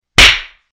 After recording myself making a single loud clap, I saved the file to my computer, and then added a button in an easy-to-reach location on the Stream Deck.
single-loud-clap-shorter.mp3